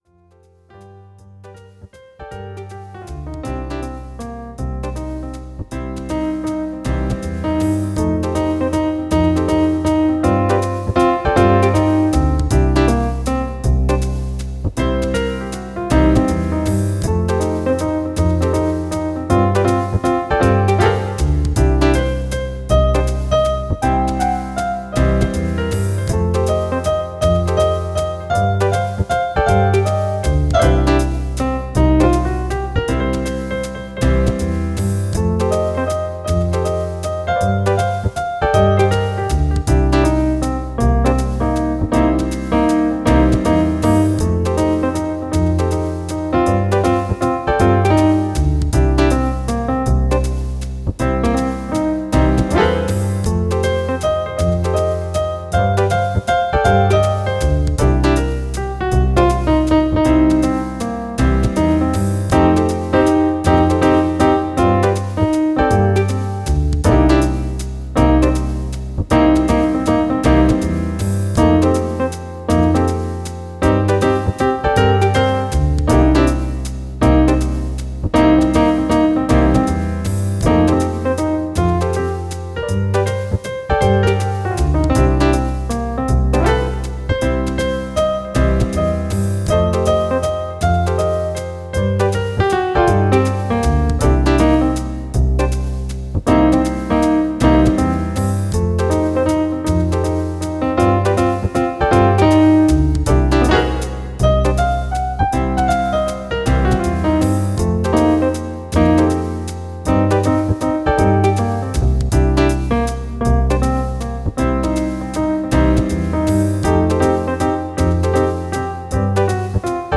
Tempo: 55 bpm / Datum: 26.02.2017